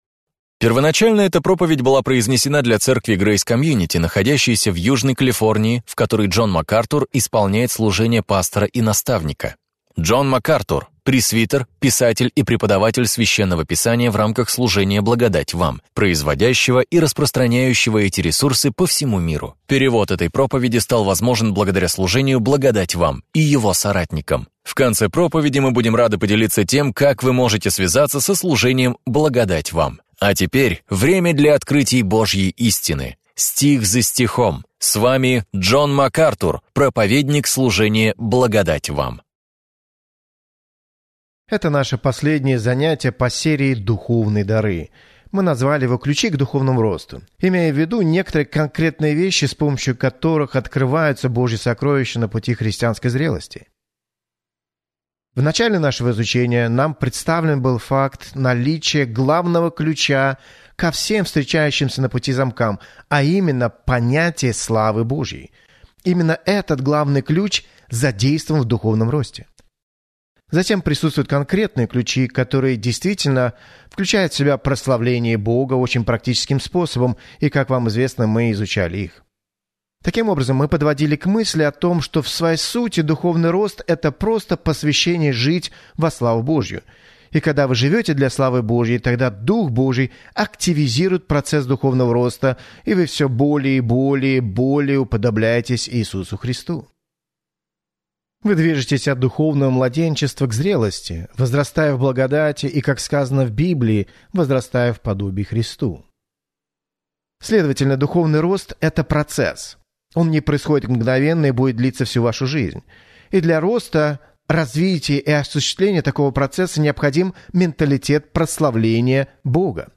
Если ваши духовные «кирпичи» прочно положены, у вас есть твердое основание для дальнейшего роста, которое поможет вам выдержать даже самые большие трудности жизни. В своей проповеди «Возвращение к основам», актуальной для всякого времени, Джон Макартур делает обзор базовых принципов христианства и помогает вам сделать их основанием для своей жизни.